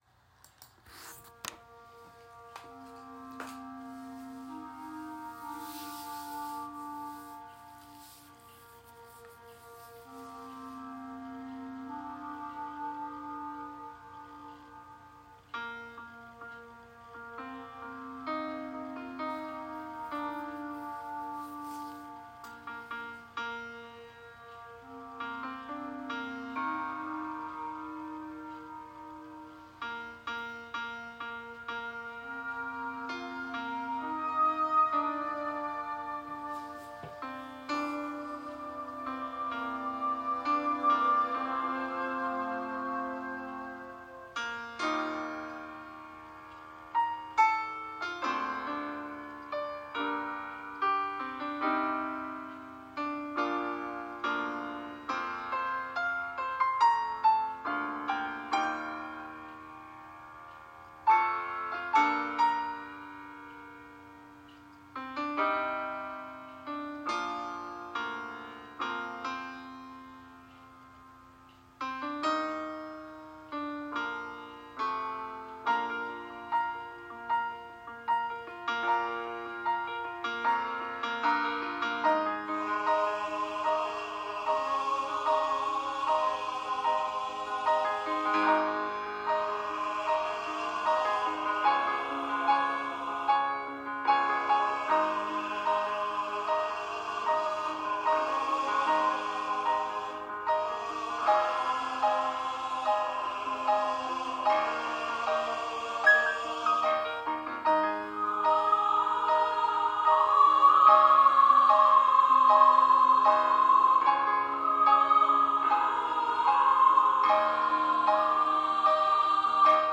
Voicing/Instrumentation: SATB , SATB quartet , Vocal Solo
Choir with Soloist or Optional Soloist Piano